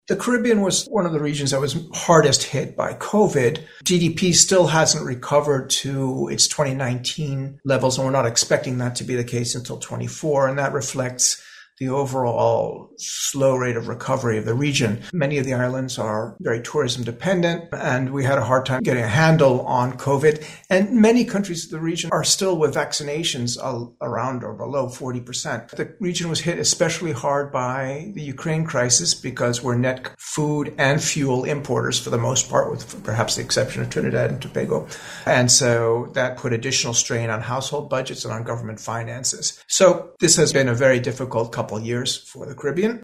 Speaking this morning during the World Bank’s presentation of its regional economic review